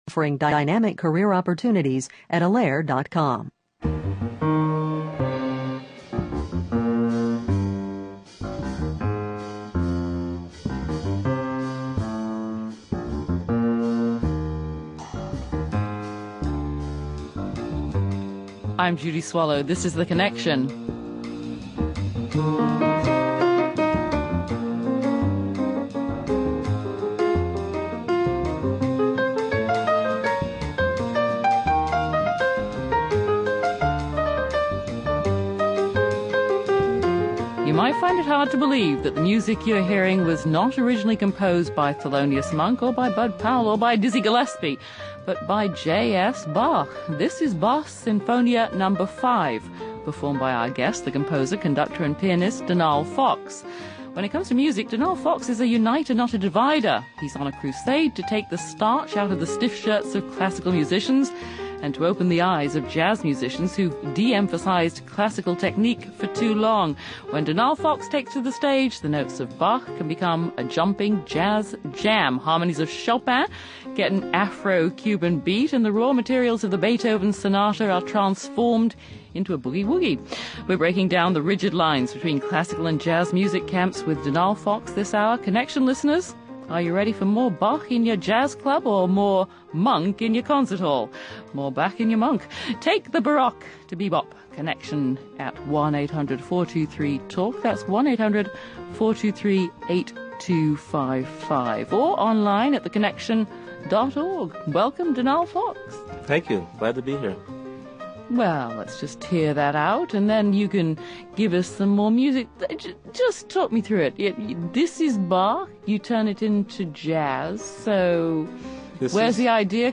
Guests: Pianist and composer